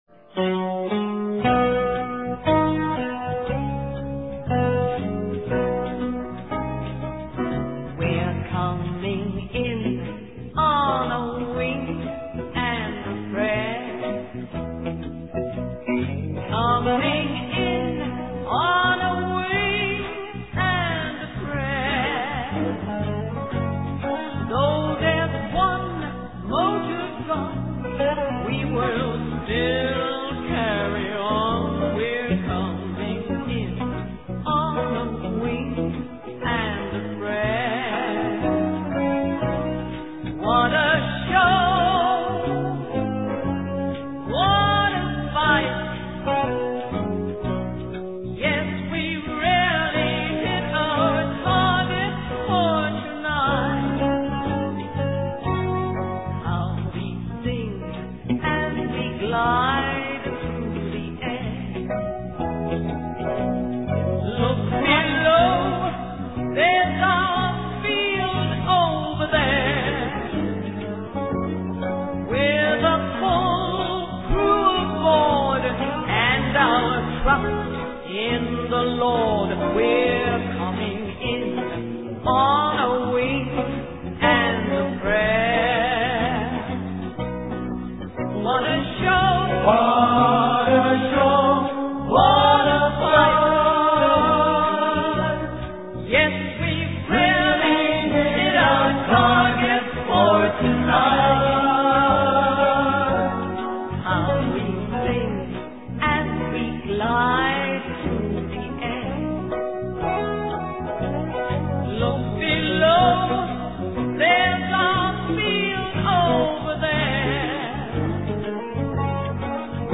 мне вообще нравится эта английская певица